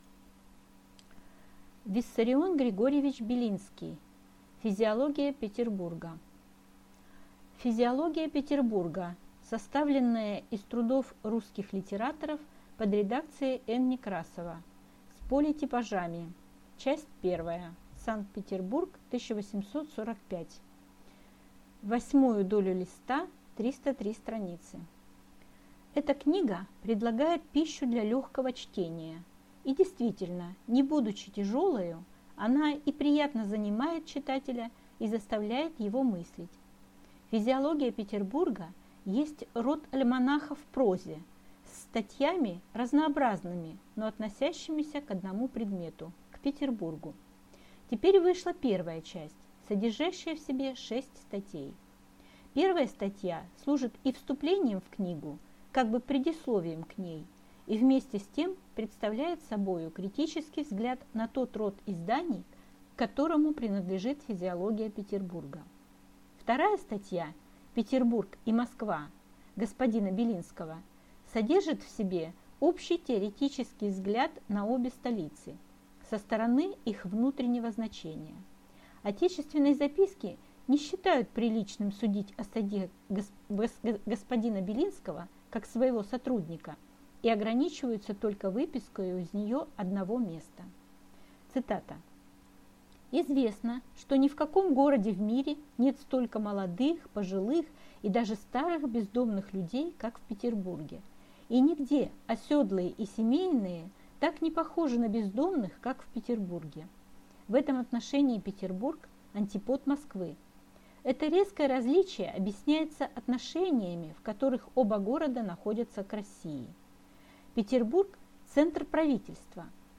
Аудиокнига Физиология Петербурга | Библиотека аудиокниг